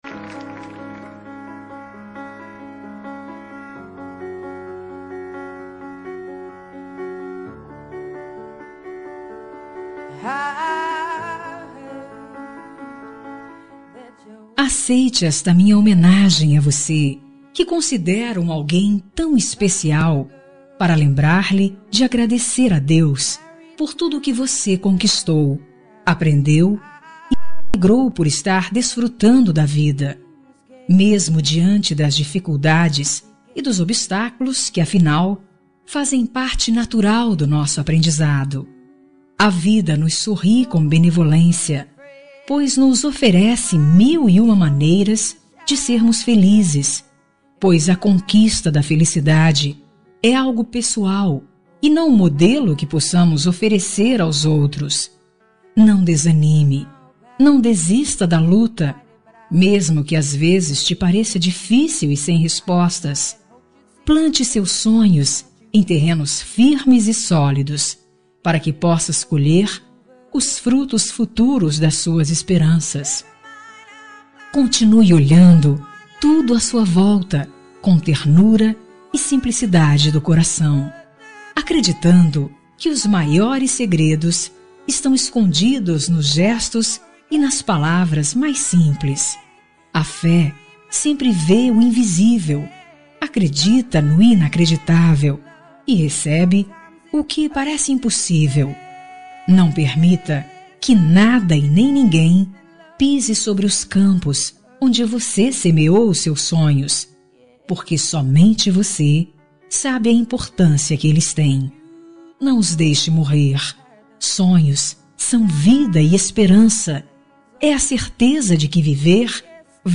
Telemensagem Você é Especial – Voz Feminina – Cód: 5419